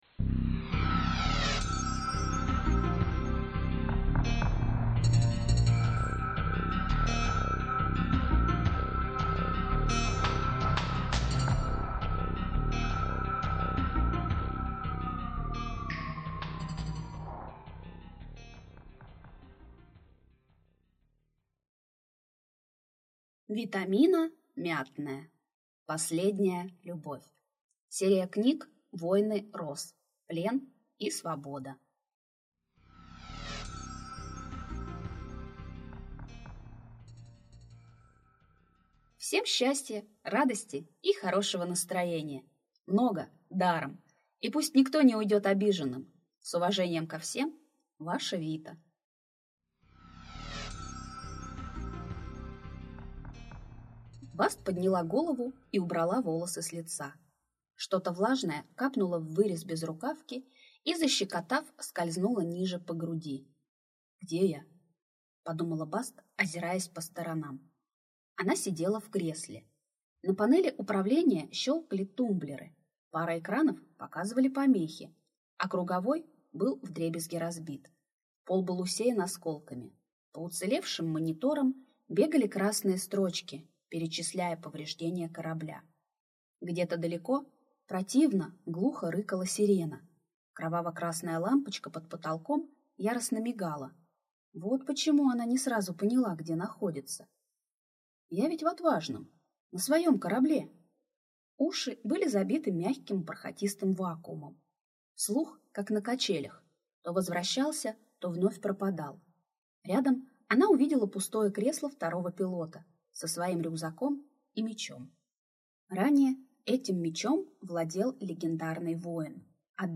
Аудиокнига Плен и свобода | Библиотека аудиокниг